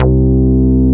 P MOOG C3F.wav